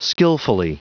Prononciation du mot skillfully en anglais (fichier audio)
Prononciation du mot : skillfully